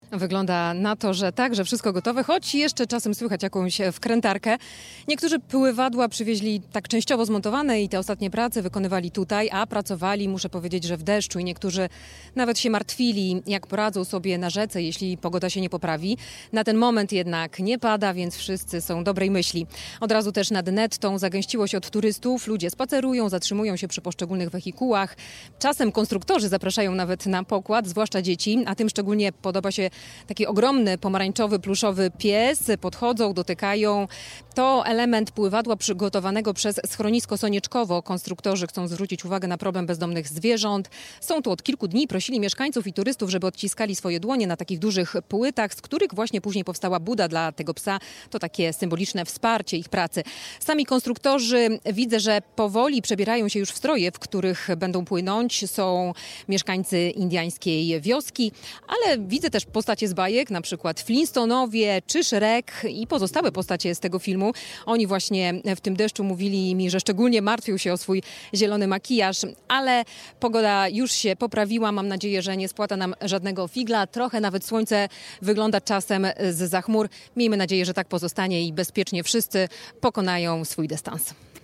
Nad Nettą zagęściło się od turystów - relacja